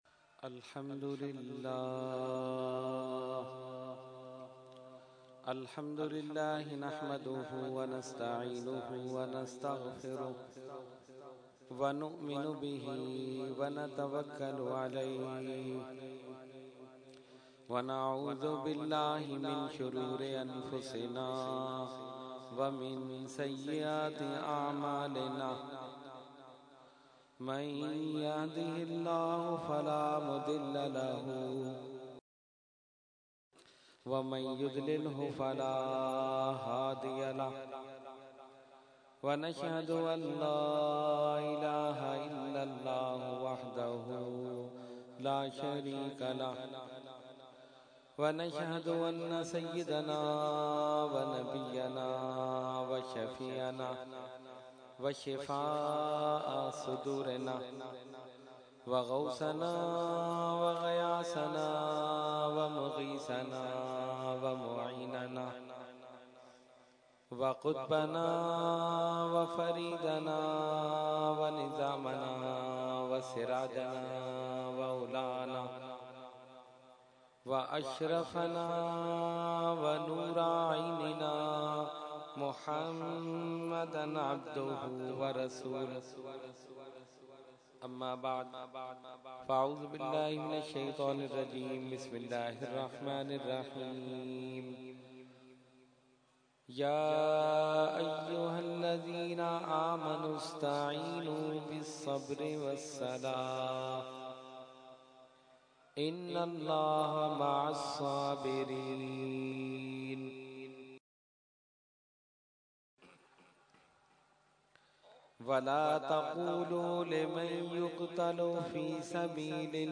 Category : Speech | Language : UrduEvent : Muharram ul Haram 2014